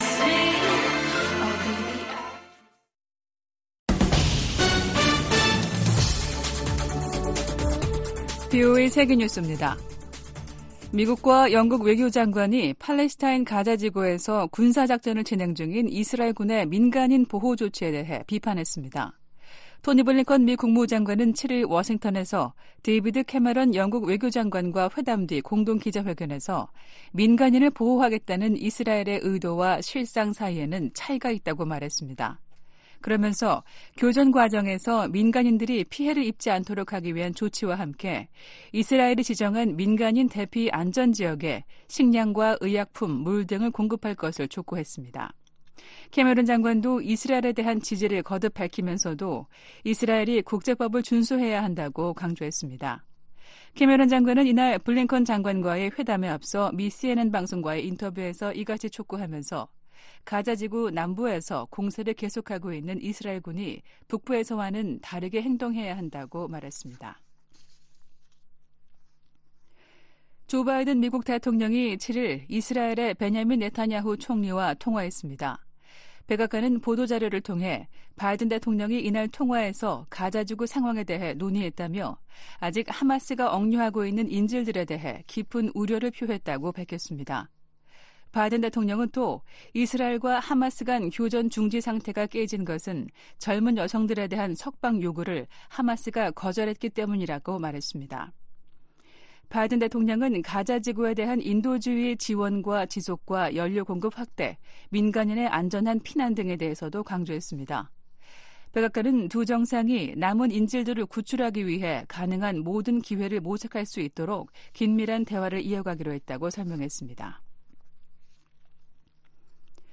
VOA 한국어 아침 뉴스 프로그램 '워싱턴 뉴스 광장' 2023년 12월 9일 방송입니다. 커트 캠벨 미 국무부 부장관 지명자가 상원 인준청문회에서 대북 억지력 강화의 필요성을 강조했습니다. 북한 정권의 불법 사이버 활동을 차단하기 위한 미국과 한국, 일본의 외교 실무그룹이 공식 출범했습니다. 미 상·하원이 9천억 달러에 달하는 내년 국방수권법 최종안을 공개했습니다.